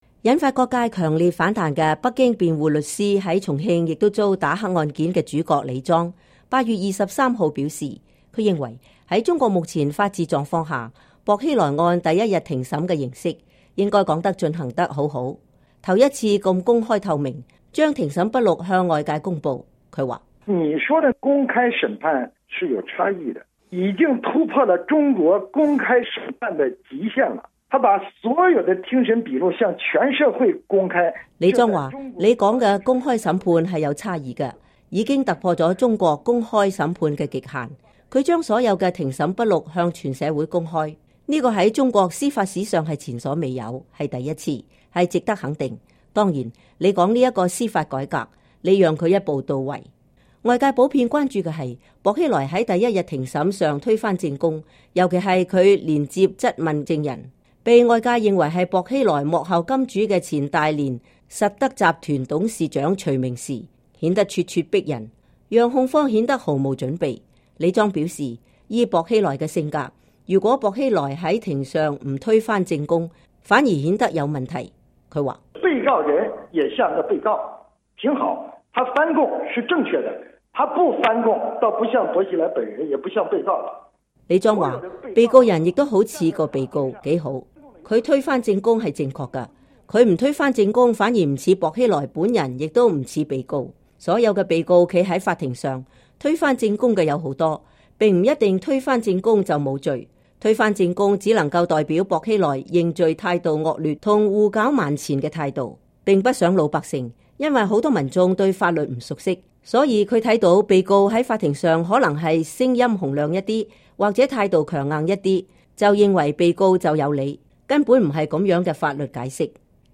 曾在重慶“唱紅打黑”中因為重慶“黑幫頭目”辯護，而在2009年12月被重慶當局以“偽造證據罪”遭判監1年半的原北京康達律師事務所知名律師李莊，星期五接受美國之音專訪，點評薄熙來案第一天庭審的情況。